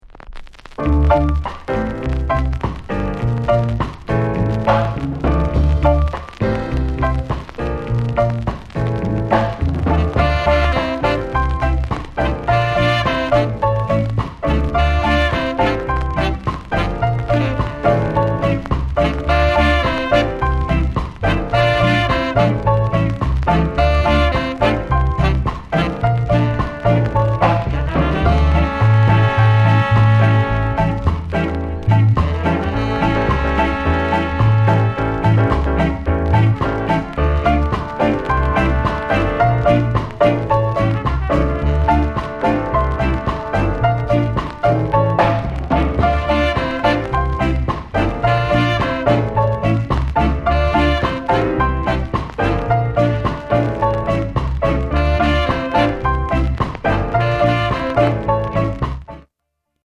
SOUND CONDITION A SIDE VG-(ok)
KILLER INST